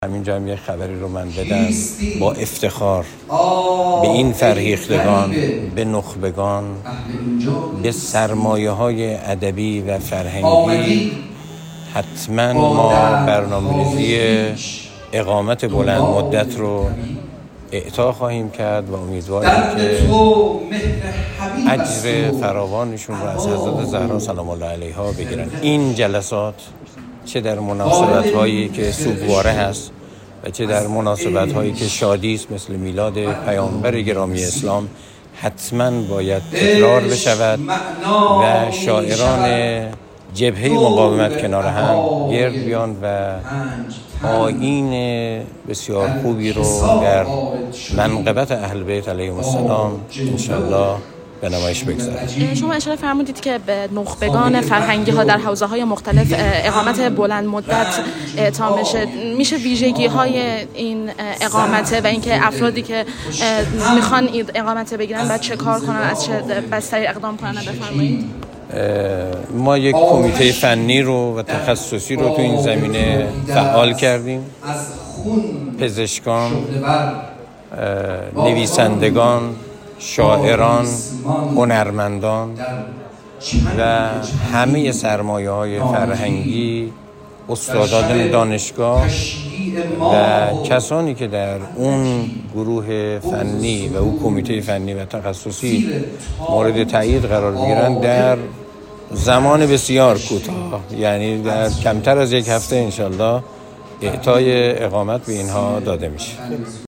امیرالله شمقدری معاون امنیتی انتظامی استانداری خراسان رضوی در حاشیه مراسم شب شعر شمیم فاطمی در مشهد در گفت‌وگو با خبرنگار رادیو دری از اعطای اقامت بلندمدت ایران به فرهیختگان، نخبگان و سرمایه‌های ادبی و فرهنگی افغانستان در اسرع وقت خبر داد.